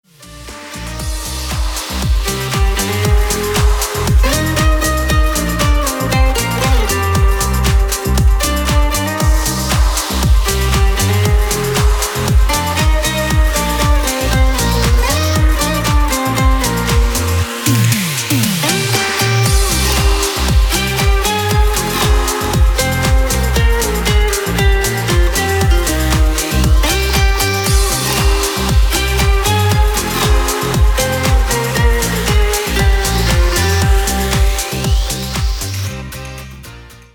• Категория: Красивые мелодии и рингтоны